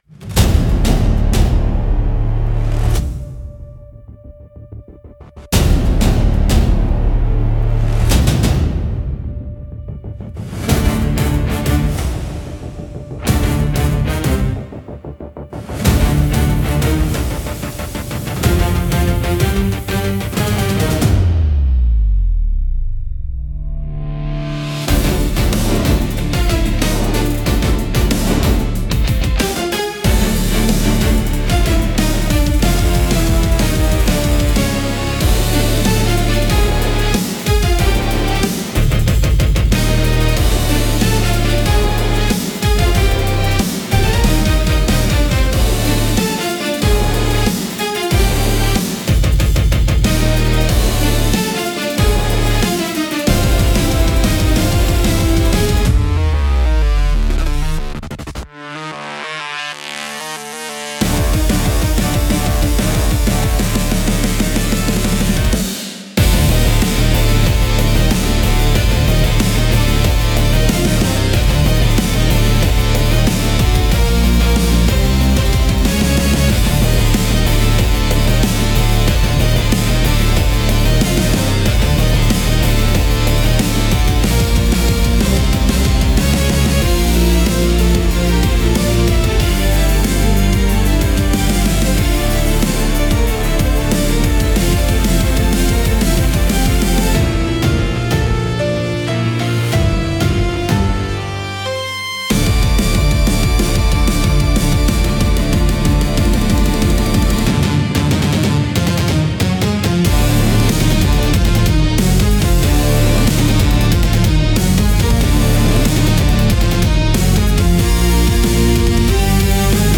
聴く人の注意を引きつけ、場の緊張感を高める効果があり、ドラマチックで切迫した演出に寄与します。